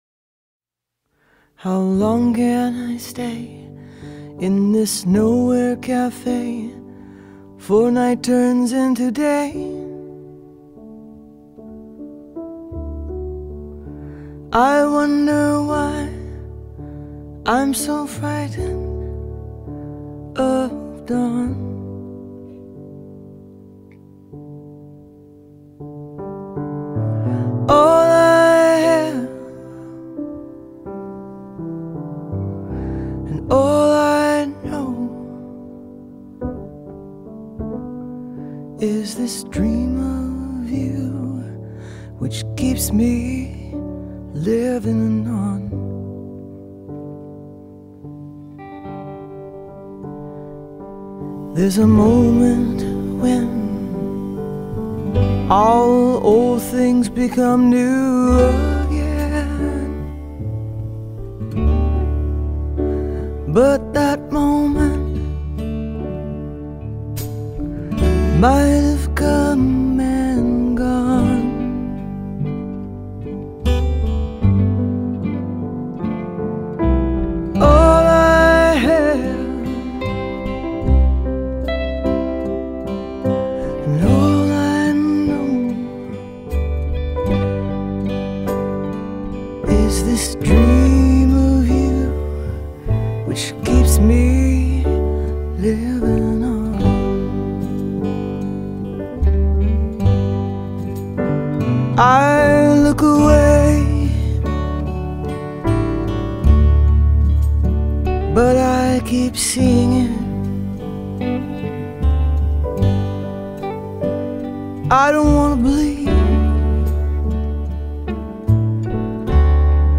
Jazz, Pop
33 دور